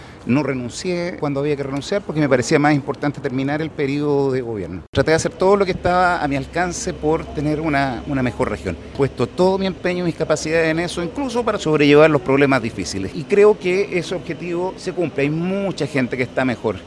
En tanto, el exgobernador, Rodrigo Díaz, detalló durante su discurso de cierre que fue un periodo duro en el que tuvo que enfrentar cuestionamientos por el Caso Convenios, problemas personales y afirmó que su reputación se vio afectada, por lo que pidió a los cercanos de Giacaman que lo cuiden.